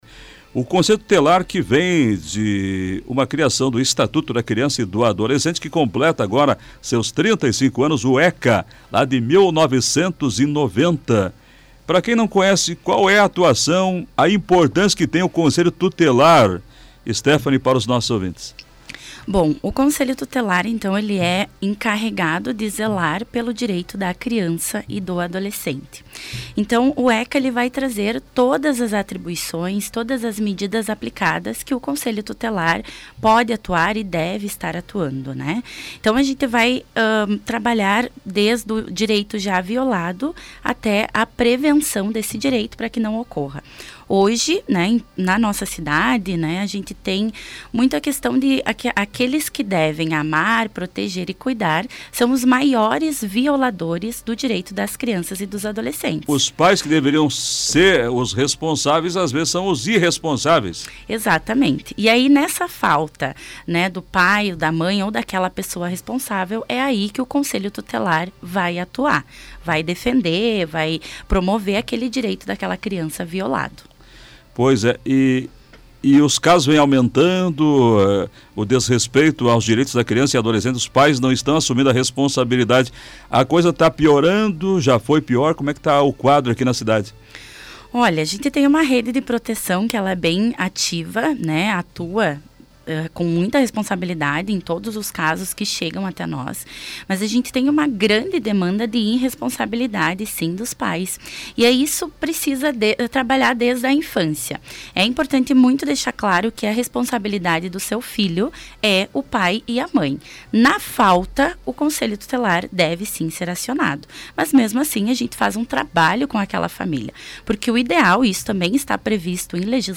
Entrevista: confira como é a atuação do Conselho Tutelar